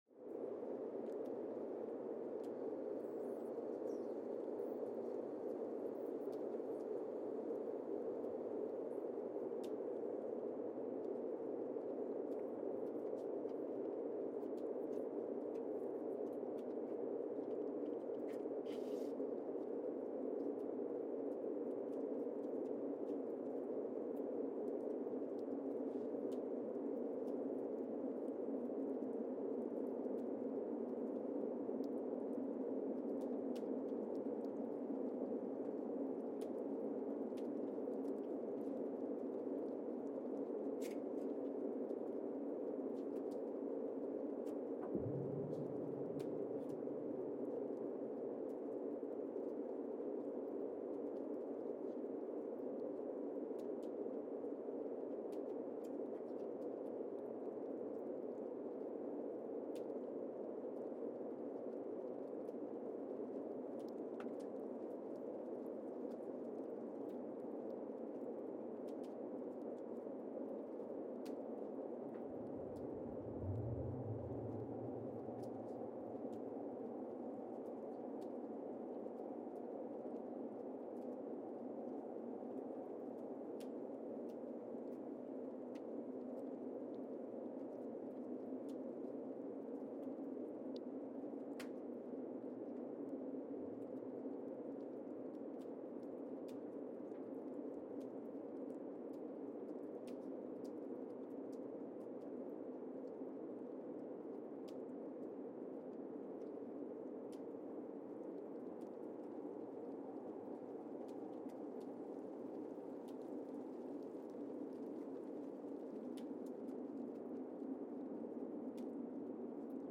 Casey, Antarctica (seismic) archived on April 30, 2025
Station : CASY (network: GSN) at Casey, Antarctica
Sensor : Streckheisen STS-1VBB
Speedup : ×1,800 (transposed up about 11 octaves)
Loop duration (audio) : 05:36 (stereo)
SoX post-processing : highpass -2 90 highpass -2 90